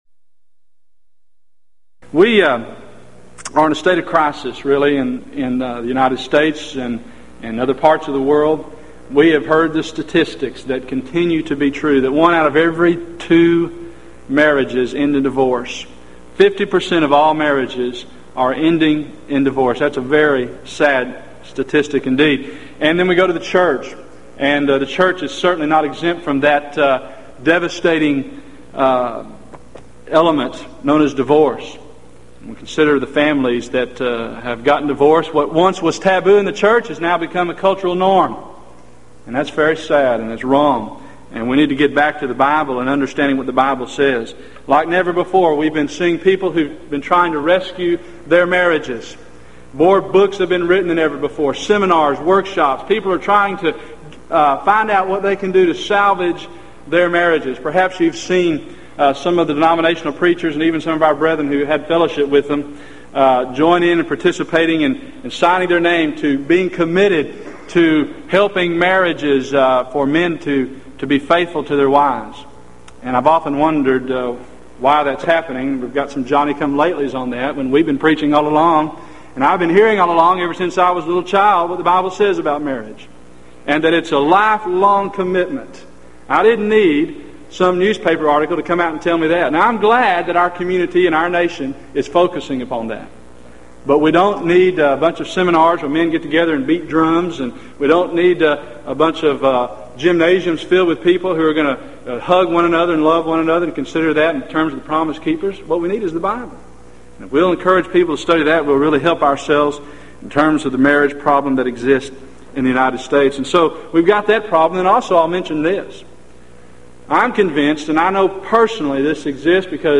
Event: 1996 Gulf Coast Lectures
lecture